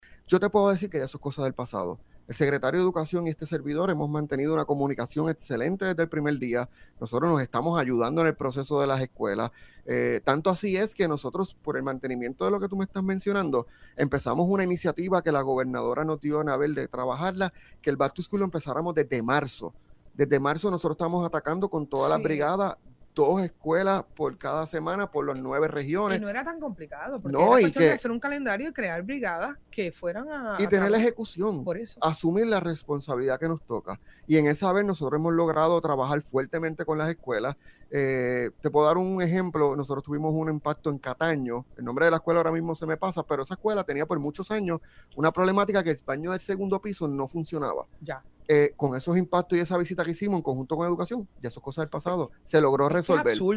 Tanto así es que nosotros, por el mantenimiento de lo que tú me estás mencionando, empezamos una iniciativa que la gobernadora nos dio […] de trabajarla, que el Back to School lo empezáramos desde marzo“, indicó Lasalle en el programa El Calentón.